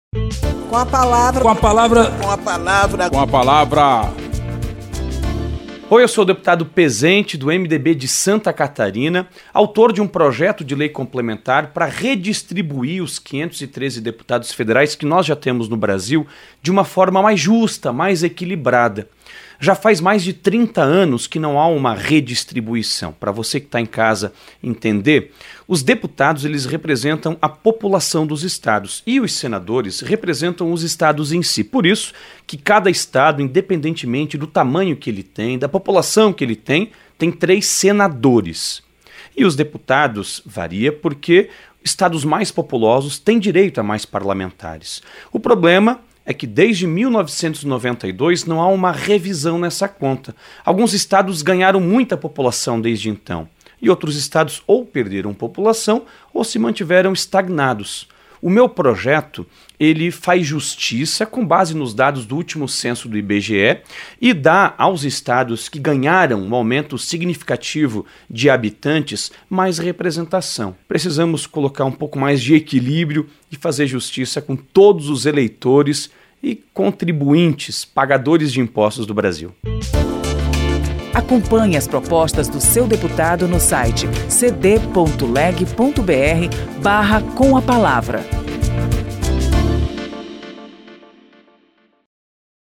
O deputado Pezenti (MDB-SC) defende redistribuição de vagas de deputados, de acordo com a atualização populacional feita pelo último censo.
Espaço aberto para que cada parlamentar apresente aos ouvintes suas propostas legislativas